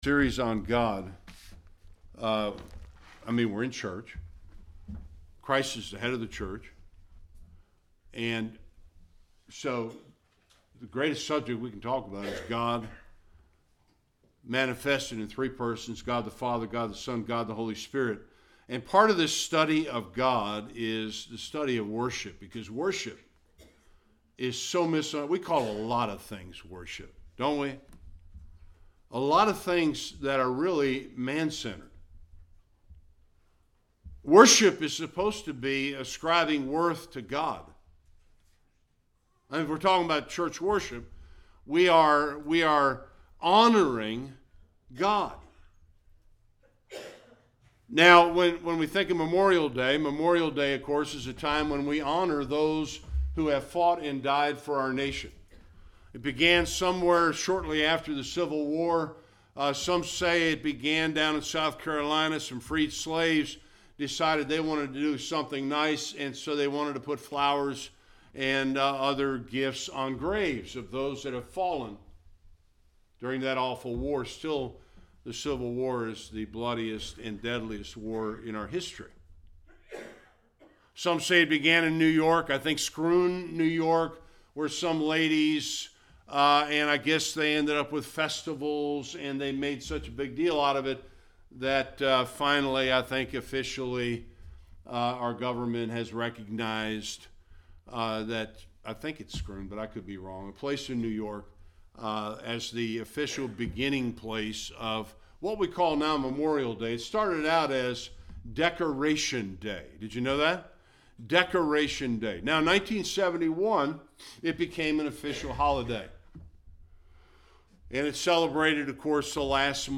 22 Service Type: Sunday Worship Signposts to prepare for worship.